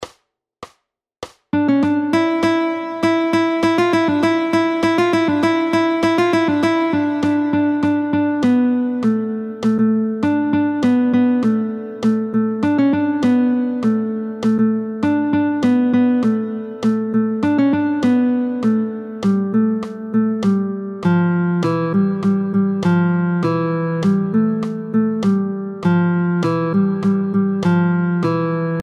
√ برای ساز گیتار | سطح متوسط